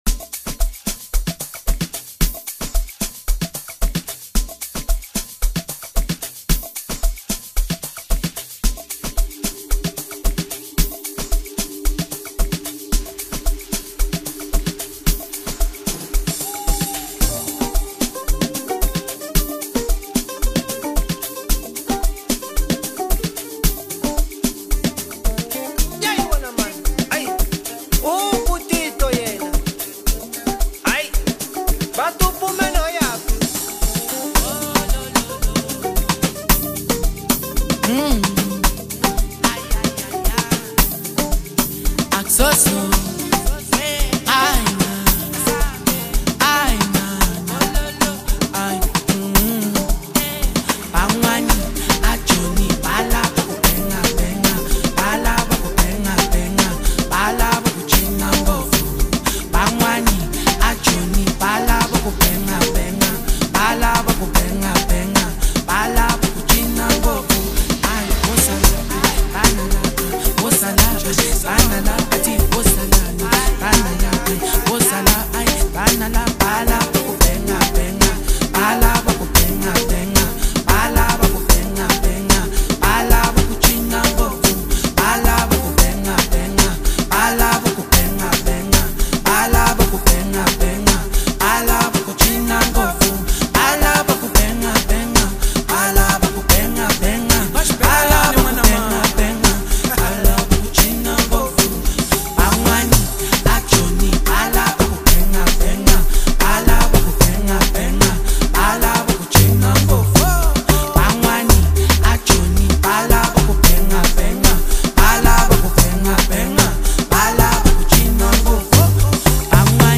AMAPIANO Apr 07, 2026